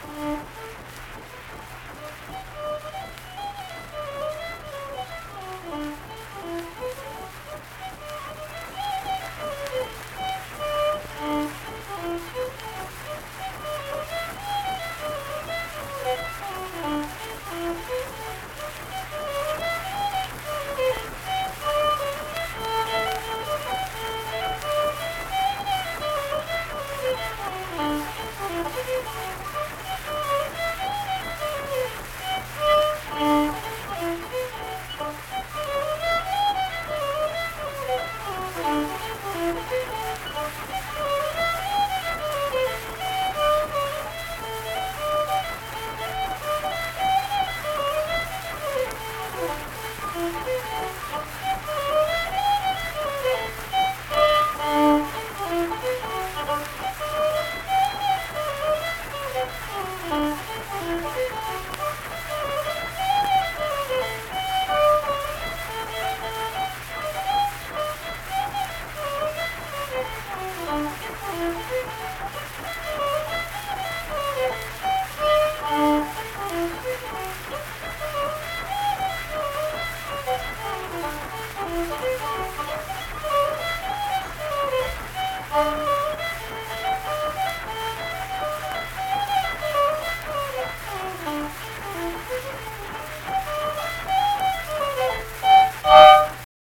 Unaccompanied fiddle music performance
Verse-refrain 4(2).
Instrumental Music
Fiddle
Harrison County (W. Va.)